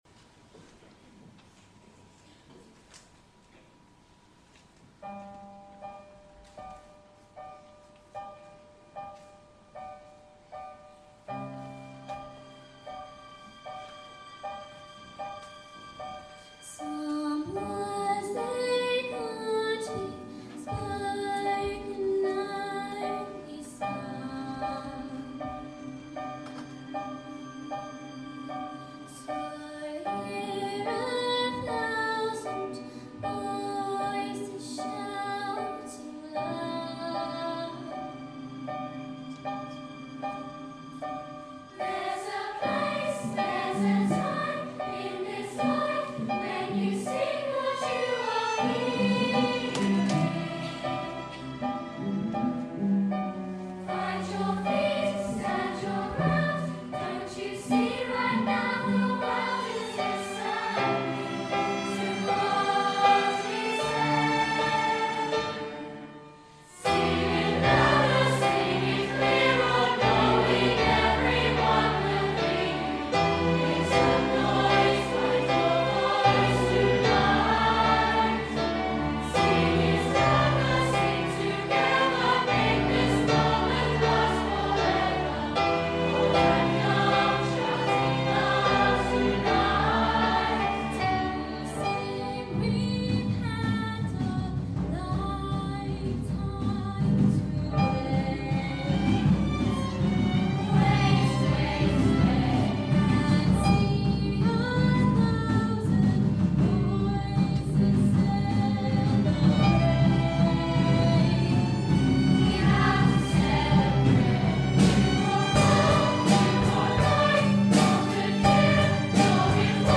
Performed by Take Note, Coro, Melodic Minors and the Orchestra